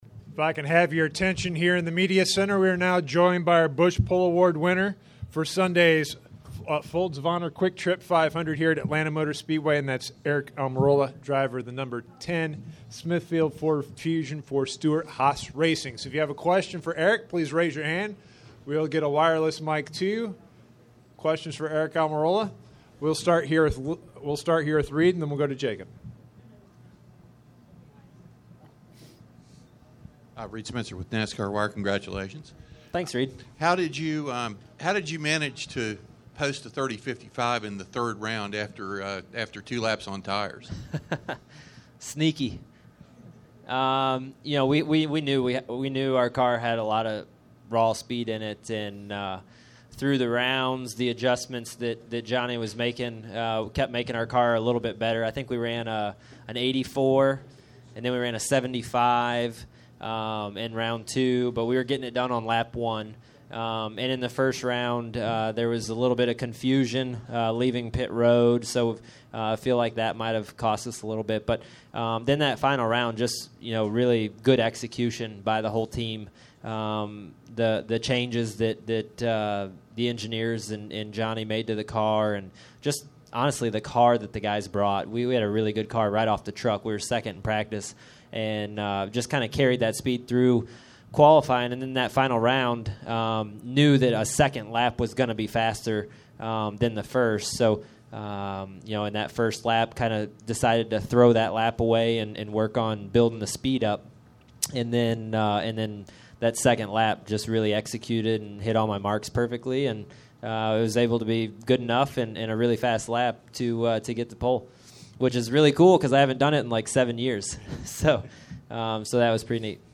Media Center Interviews: